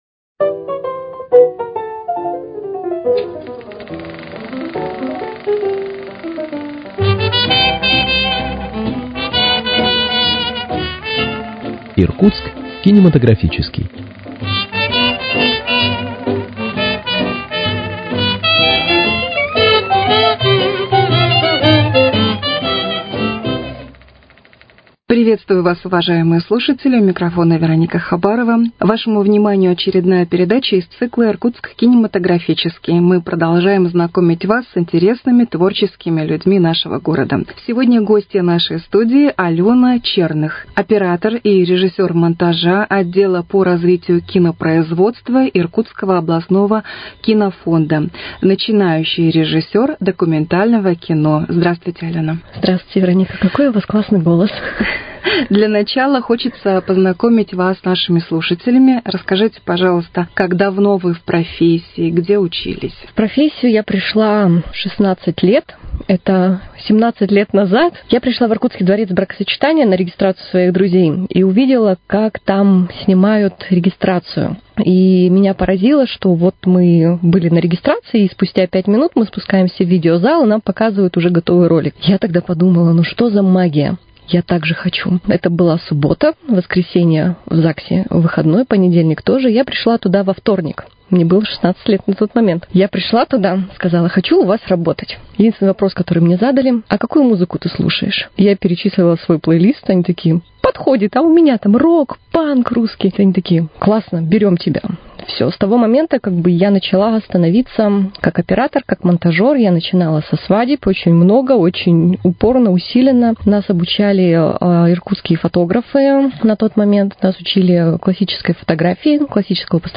Иркутск кинематографический: Беседа